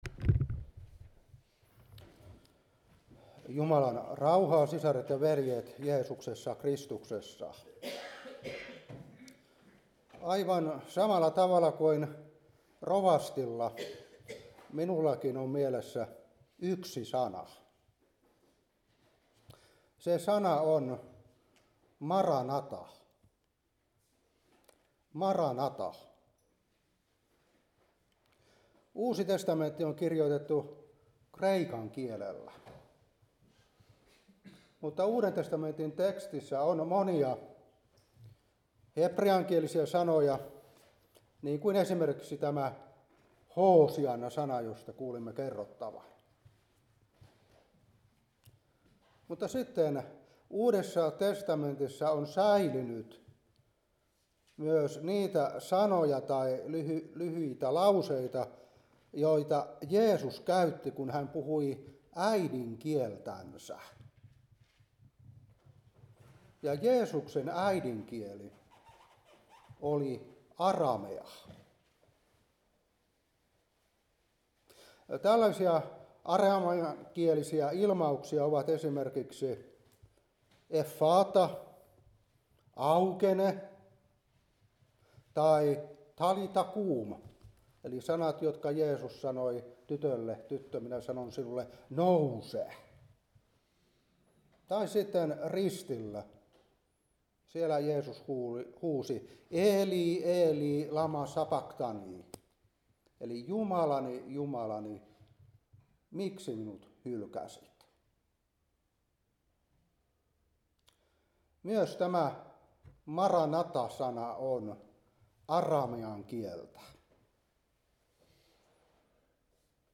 Seurapuhe 2025-12.